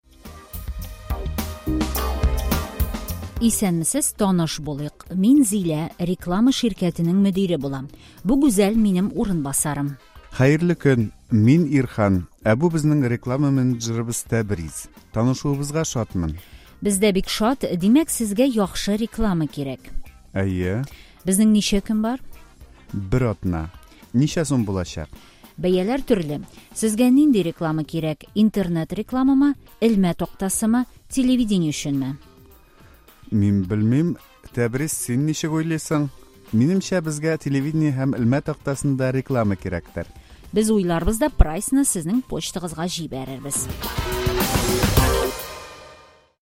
Диалог №1: Таныш булыйк!